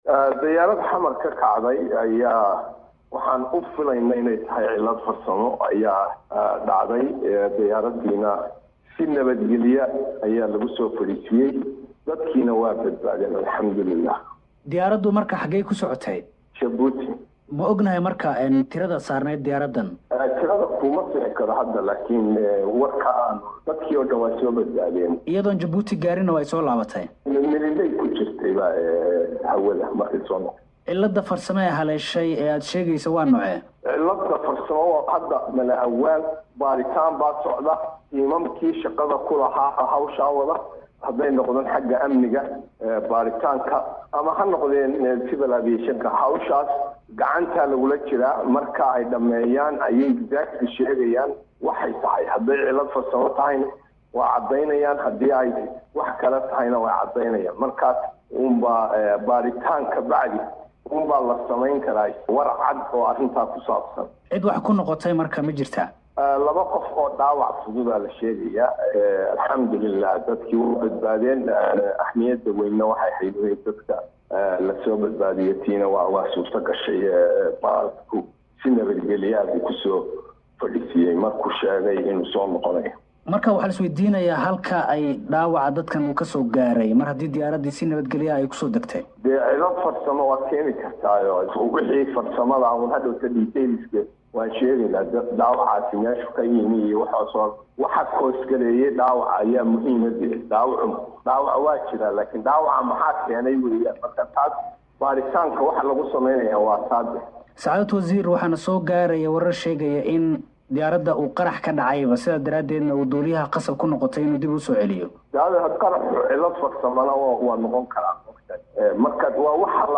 Maxaan ka ognahay diyaarad hawada qarax ka soo gaaray: dhegeyso wasiirka duulista